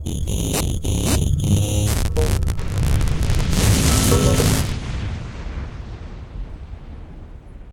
PixelPerfectionCE/assets/minecraft/sounds/portal/trigger.ogg at bbd1d0b0bb63cc90fbf0aa243f1a45be154b59b4